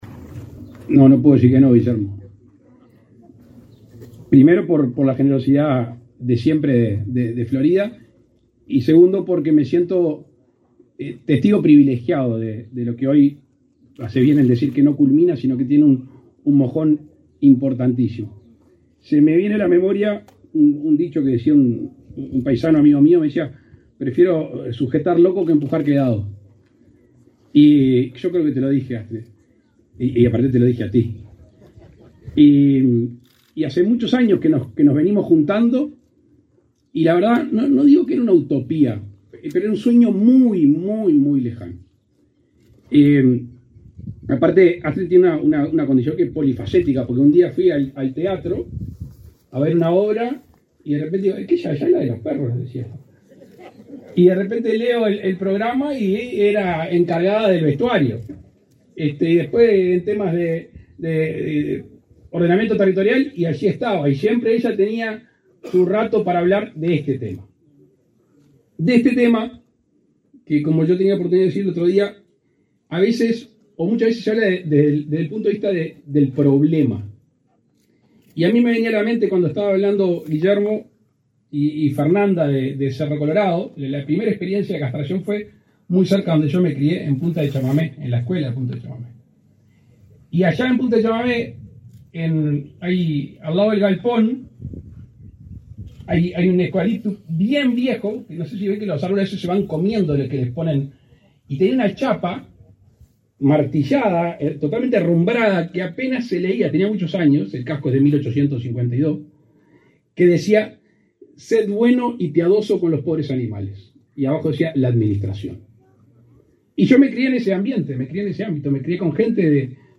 Palabras del presidente de la República, Luis Lacalle Pou
Con la presencia del presidente de la República, Luis Lacalle Pou, se inauguró, este 27 de setiembre, el primer refugio transitorio para animales